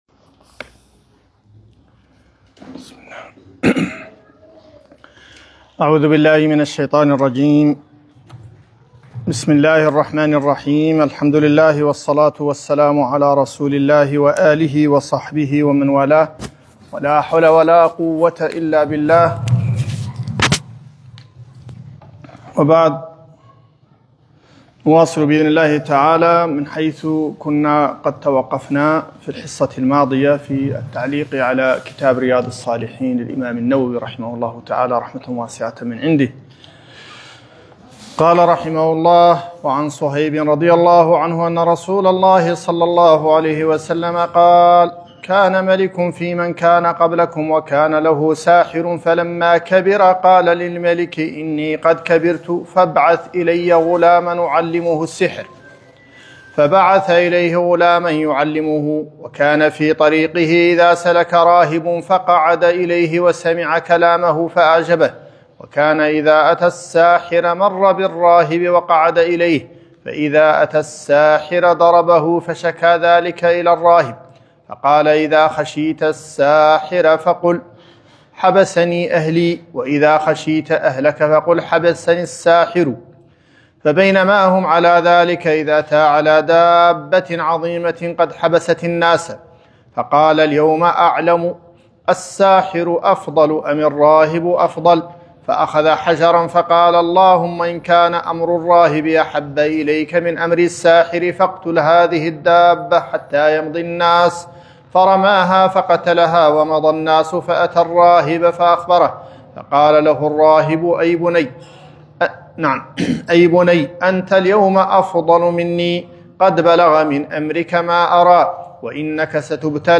رياض الصالحين الدرس 04